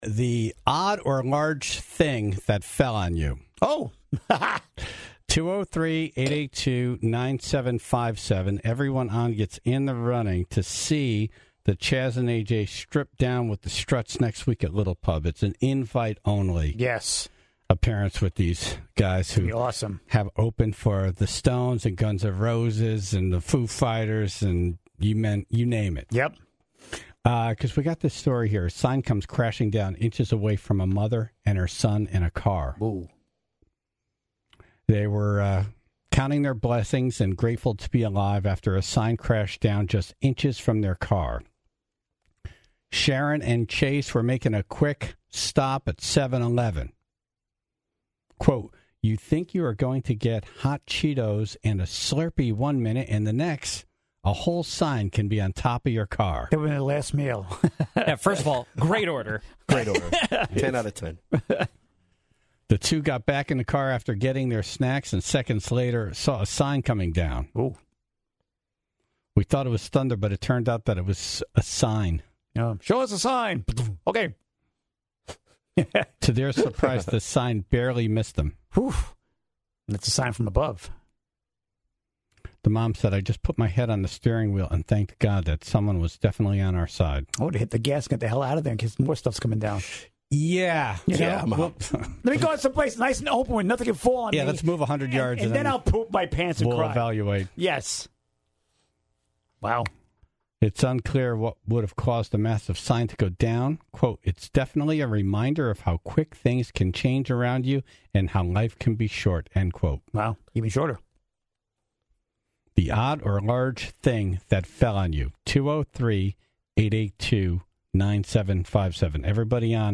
then the Tribe called in to share their stories.